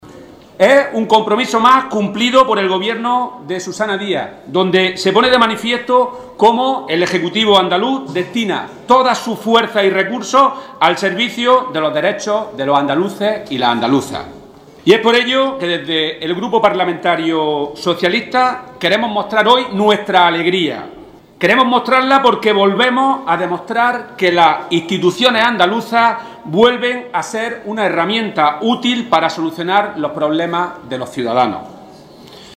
En su intervención ante la cámara, donde ha defendido la posición del Grupo Parlamentario Socialista, Rodrigo Sánchez ha mostrado su satisfacción por este cambio normativo.